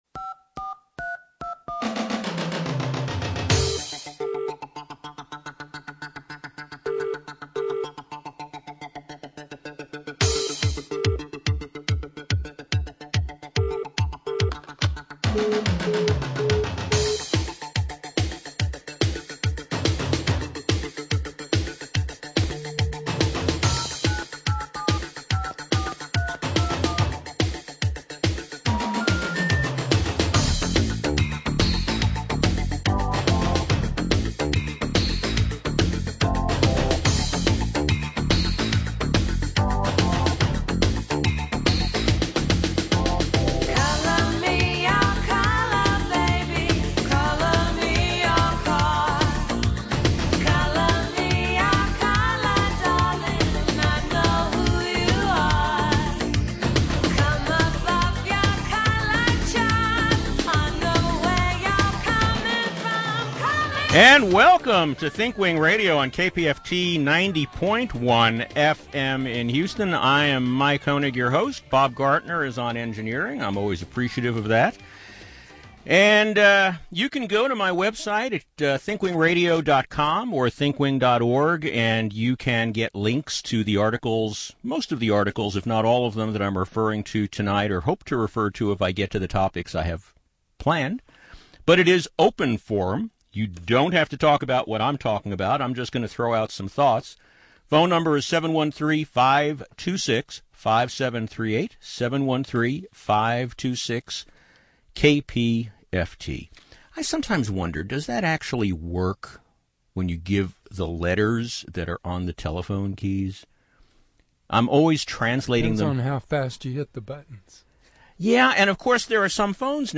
We will be taking callers during this show.